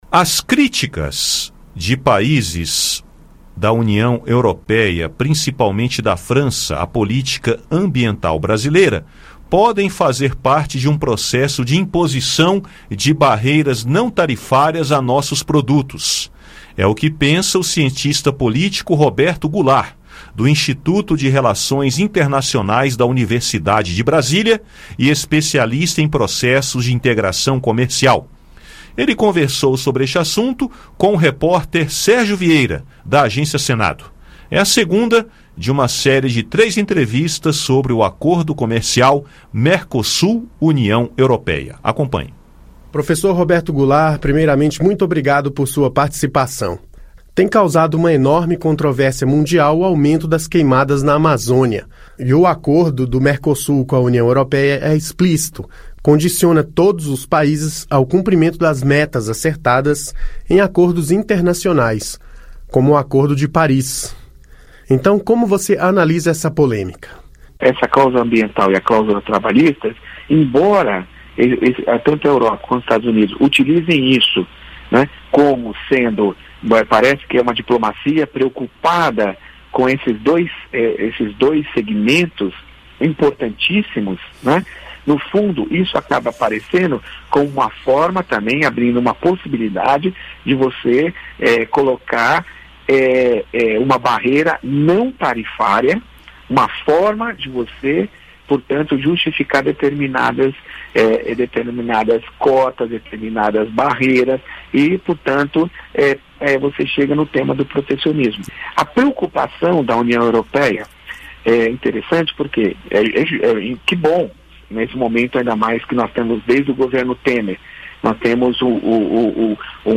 Ouça o áudio com a entrevista, que é a segunda de três que realizamos no tema do recente acordo comercial entre a União Europeia e o Mercosul.